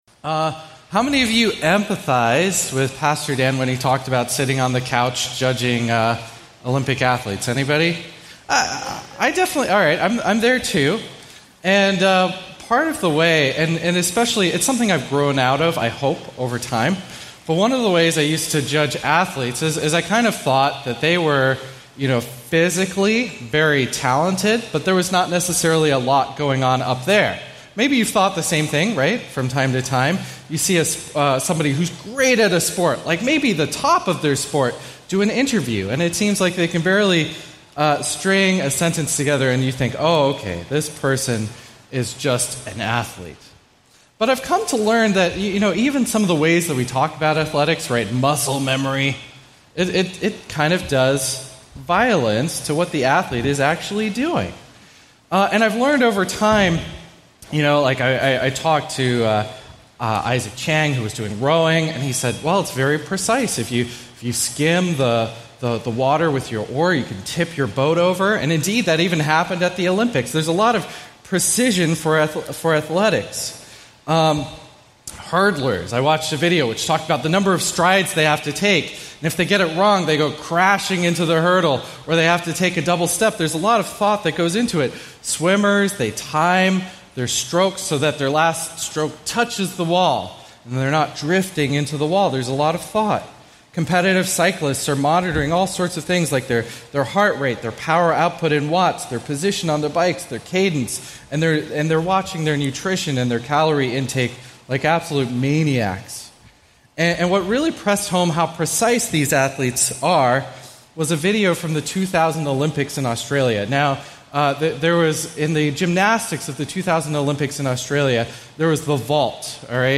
A message from the series "Ecclesiastes."